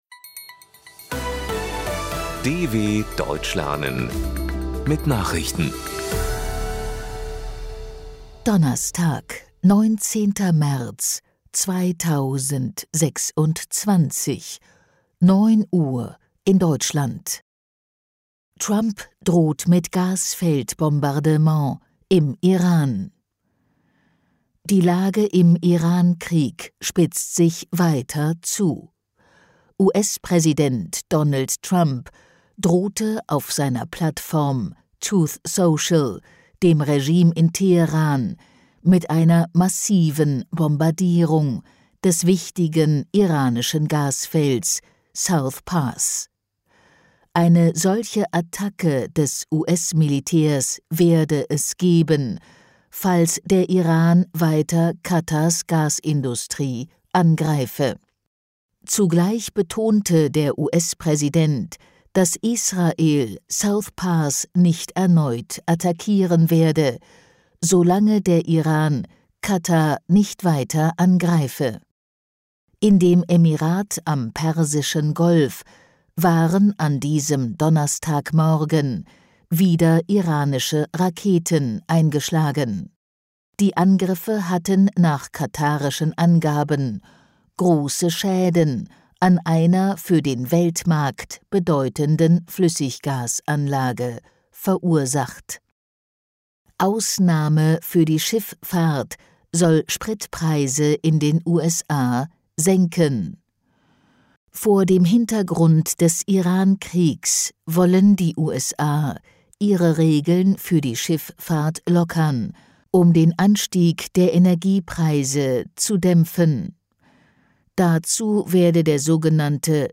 19.03.2026 – Langsam Gesprochene Nachrichten
19.03.2026 – Langsam Gesprochene Nachrichten – Trainiere dein Hörverstehen mit den Nachrichten der DW von Donnerstag – als Text und als verständlich gesprochene Audio-Datei.